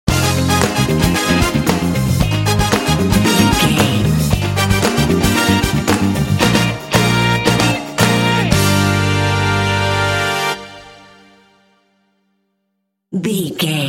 Aeolian/Minor
funky
groovy
bright
lively
energetic
bass guitar
electric guitar
electric organ
drums
strings
brass
Funk
jazz